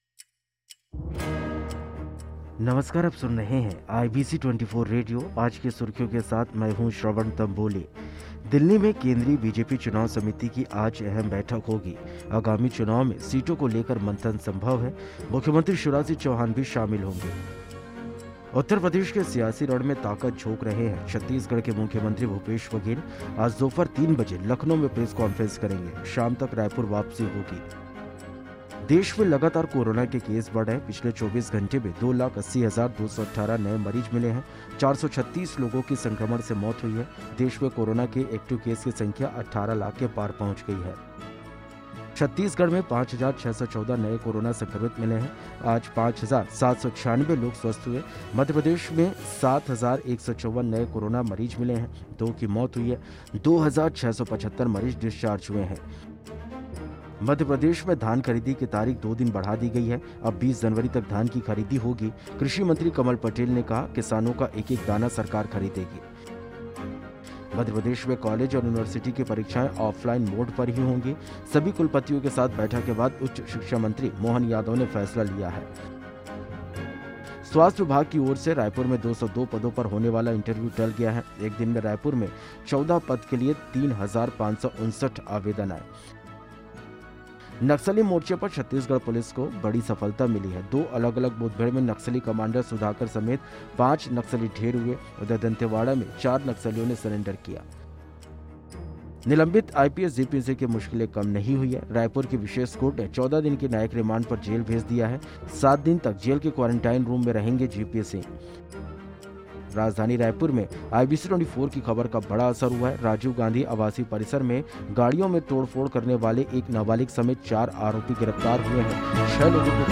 आज की सुर्खियां
Today's headlines